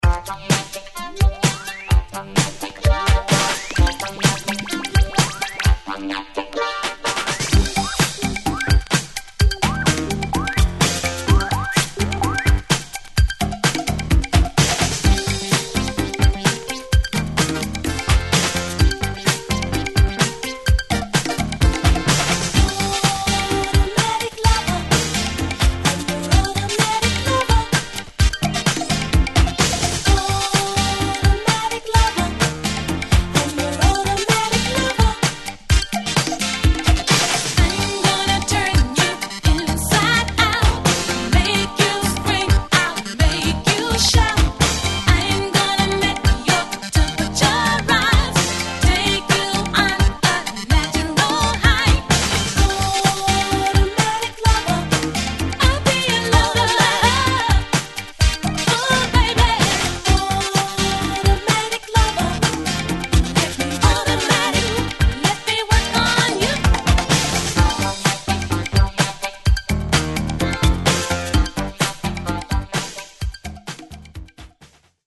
NY Electro Disco!!